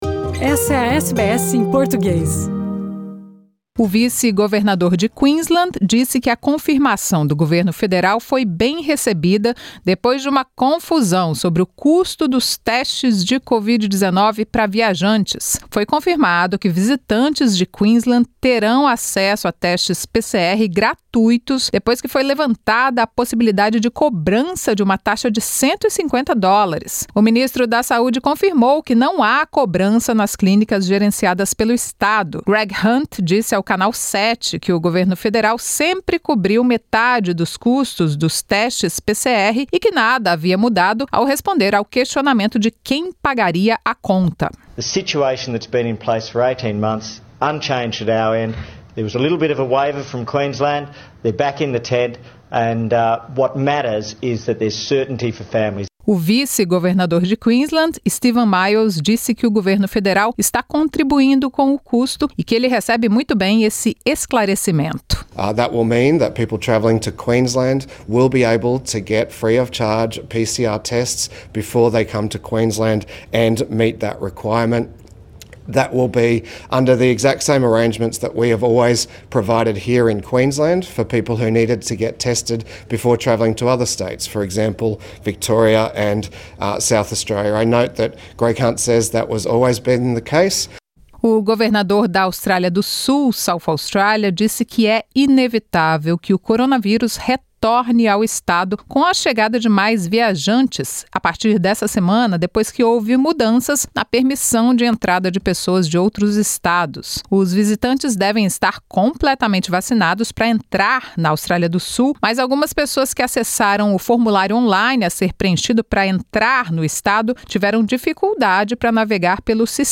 As notícias da Austrália e do Mundo da Rádio SBS para esta quarta-feira.